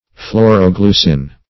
Search Result for " phloroglucin" : The Collaborative International Dictionary of English v.0.48: Phloroglucin \Phlor`o*glu"cin\, n. [Phloretin + Gr. glyky`s sweet.]
phloroglucin.mp3